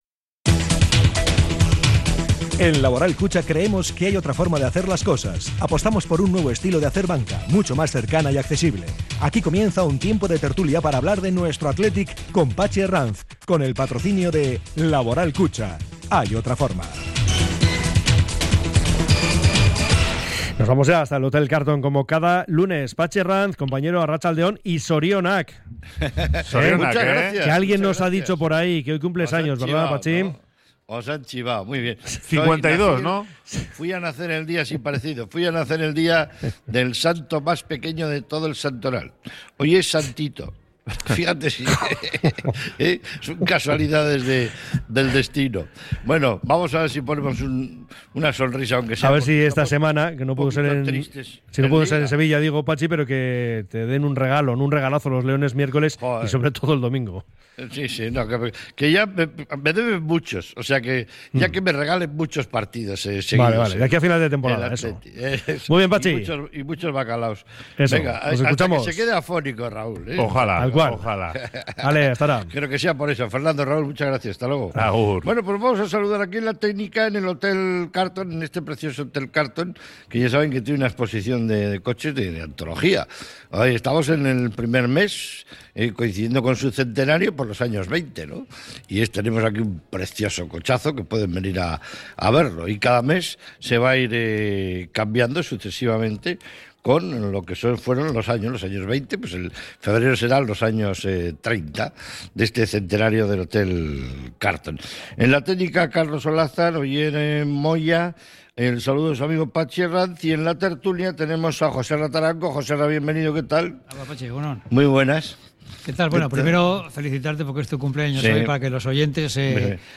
sus invitados desde el Hotel Carlton